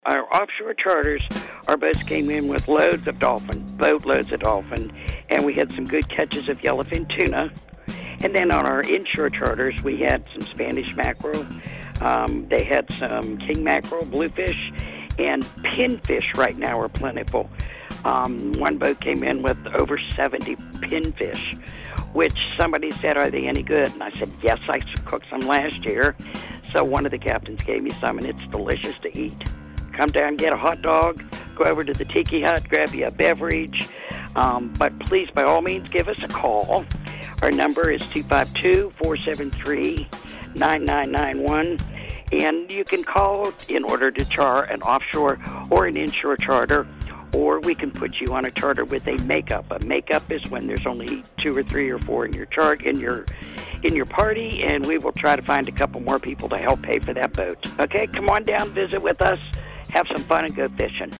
Pirate Fishing Report